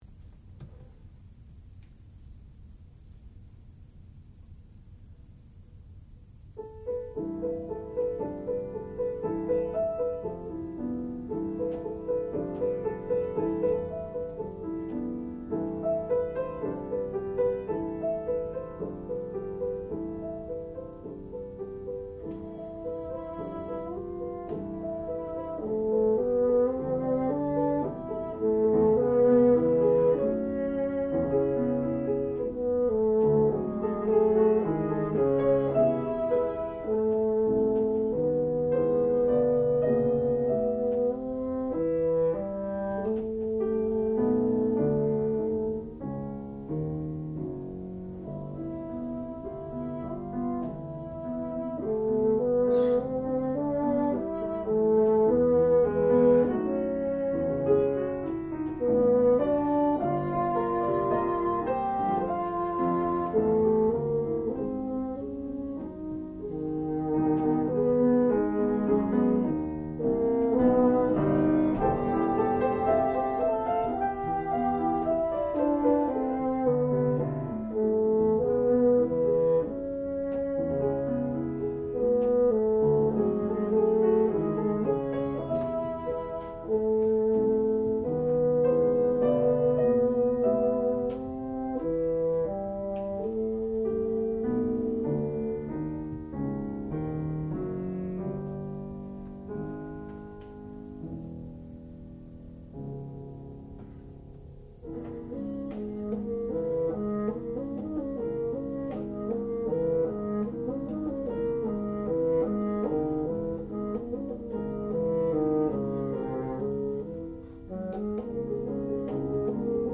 classical music
piano
bassoon
Junior Recital